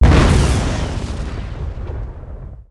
grenade_explode.ogg